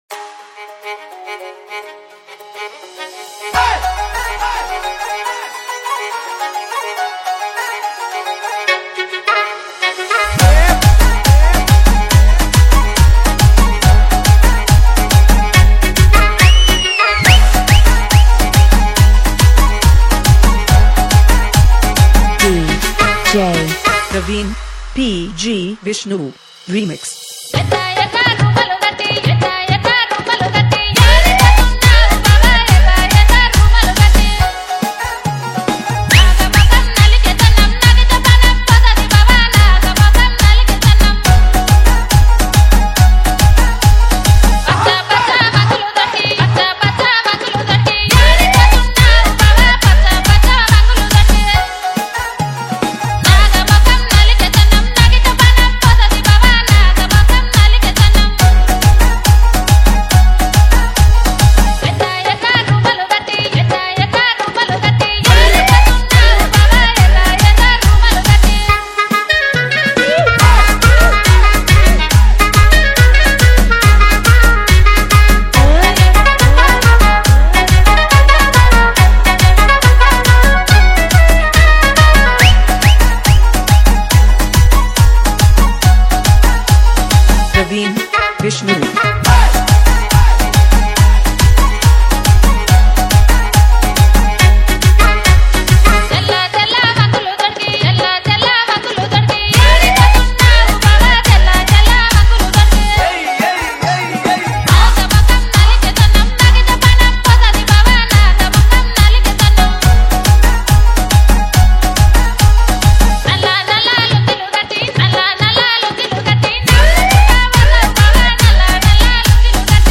Bhojpuri Romantic DJ Remix